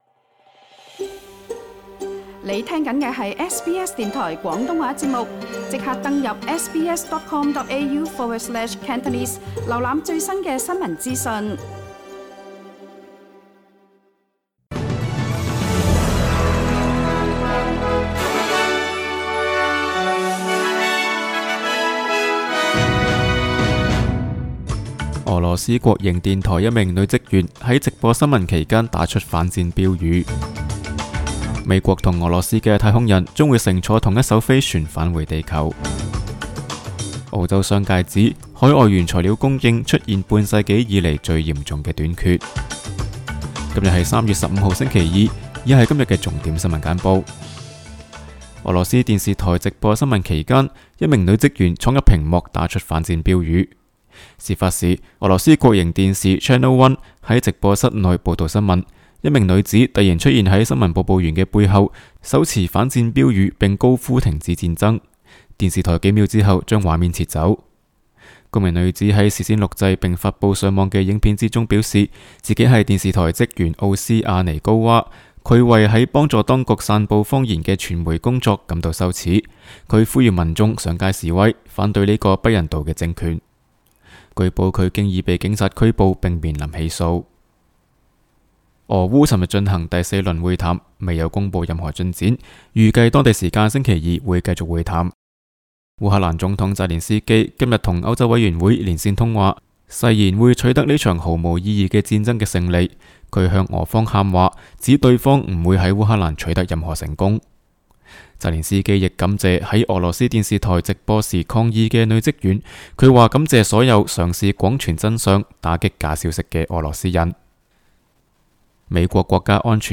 SBS 新闻简报（3月15日）
SBS 廣東話節目新聞簡報 Source: SBS Cantonese